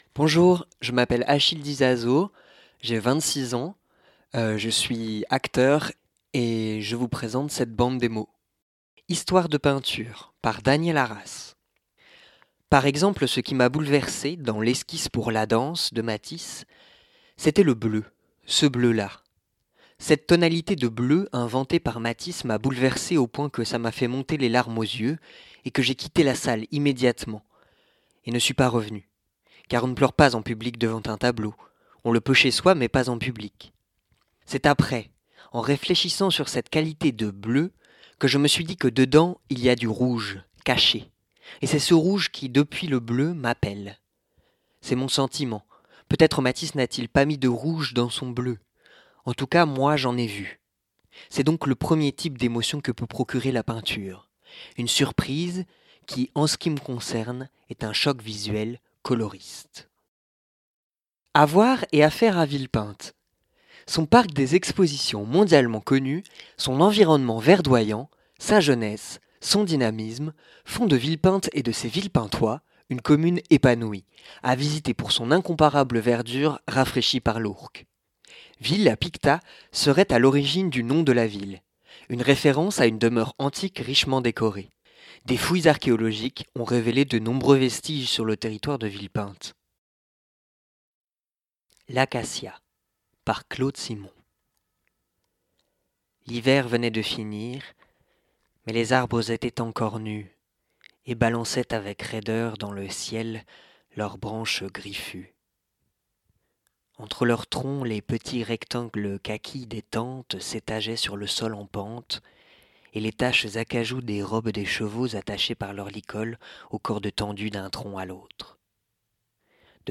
Bande démo Voix